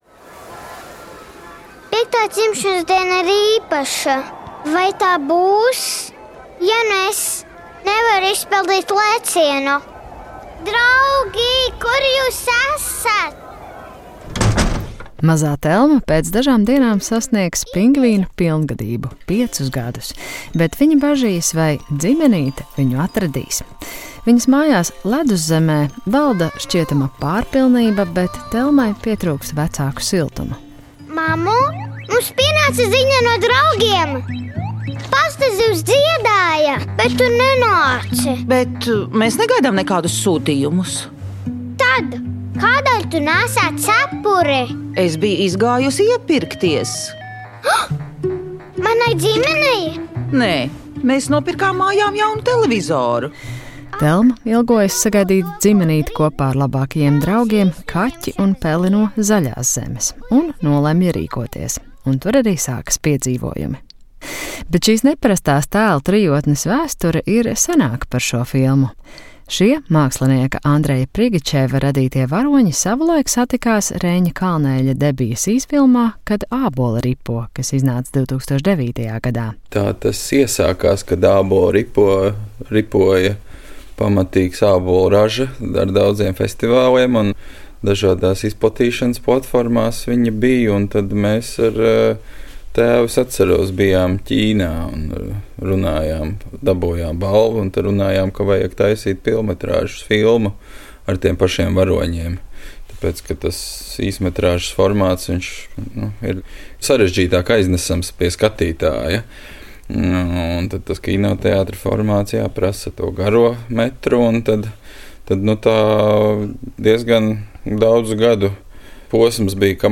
"Kultūras rondo" ir kvalitatīvākais un daudzpusīgākais radio raidījums par kultūras procesiem Latvijā un pasaulē, kas sniedz arī izvērstas anotācijas par aktuāliem notikumiem mūzikā, mākslā, kino, teātrī, literatūrā, arhitektūrā, dizainā u.c. "Kultūras rondo" redzes lokā ir tā kultūrtelpa, kurā pašreiz dzīvojam. Mēs ne tikai palīdzam orientēties kultūras notikumos, bet tiešraides sarunās apspriežam kultūras notikumu un kultūras personību rosinātas idejas.